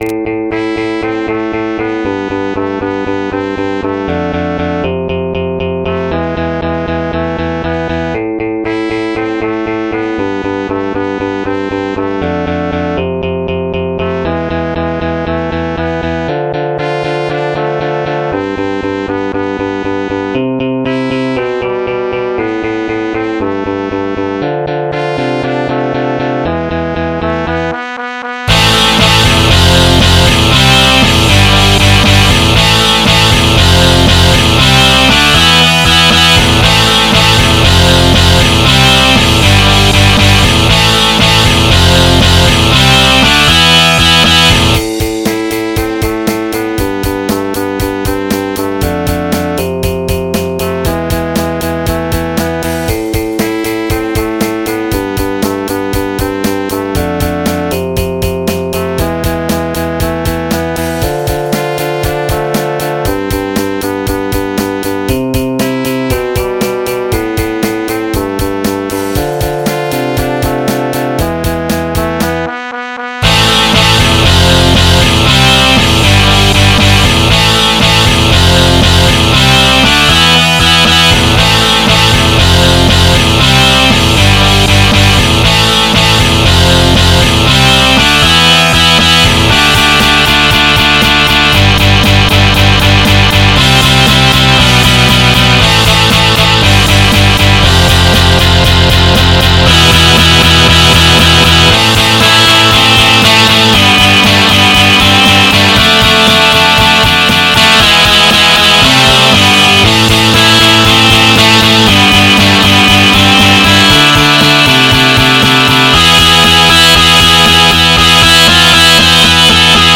MIDI 31.52 KB MP3